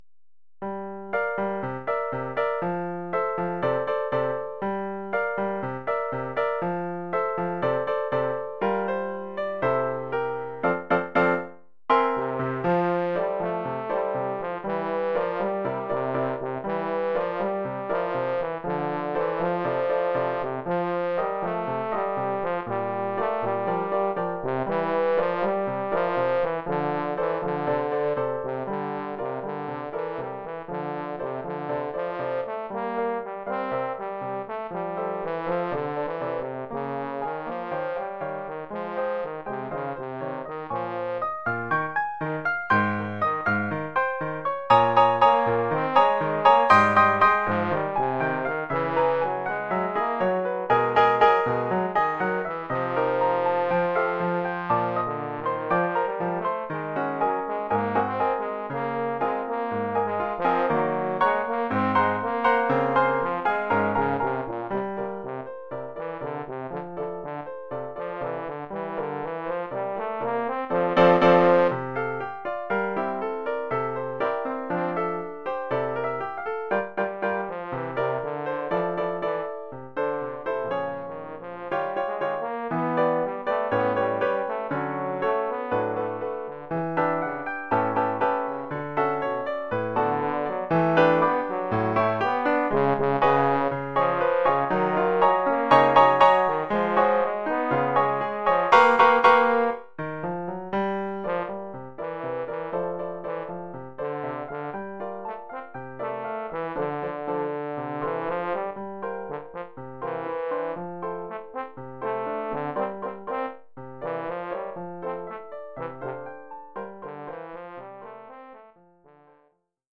Oeuvre pour trombone et piano.